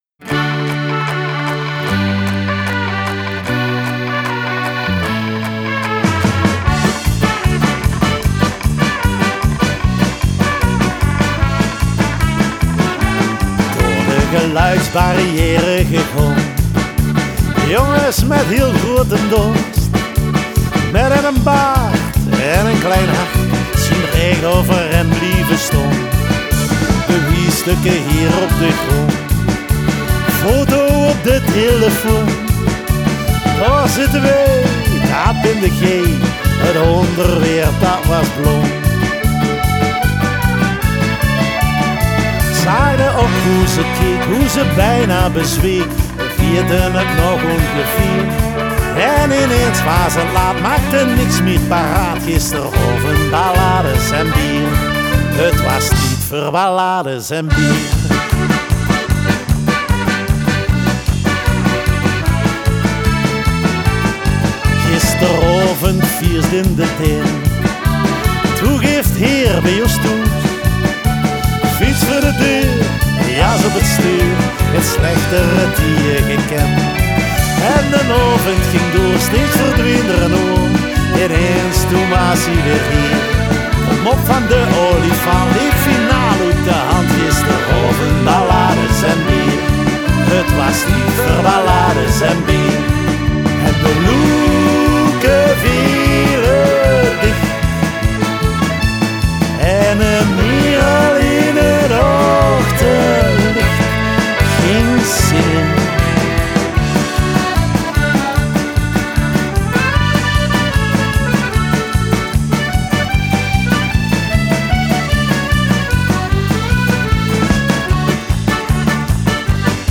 Они поют на северном нижне-франкском диалекте.
Genre: Ballad, folk